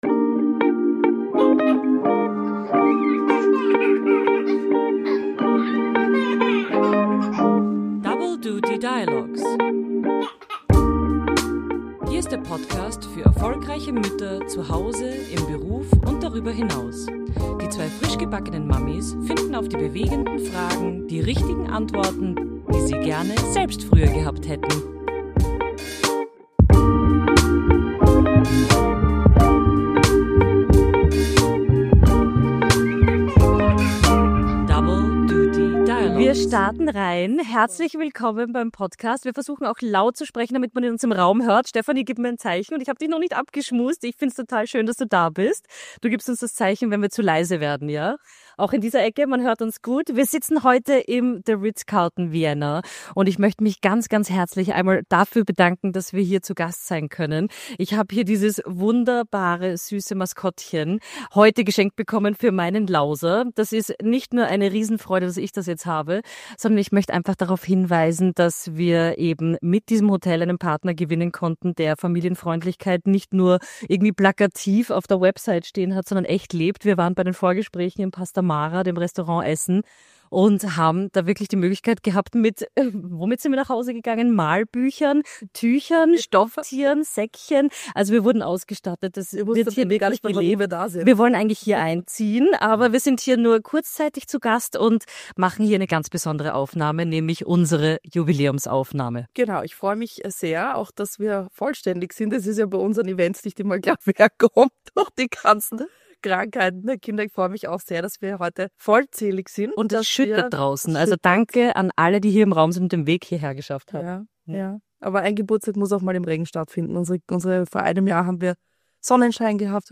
Die Räumlichkeiten wurden uns in dieser Folge von The Ritz-Carlton Vienna zur Verfügung gestellt und wir bedanken uns sehr für die Möglichkeit und die Unterstützung!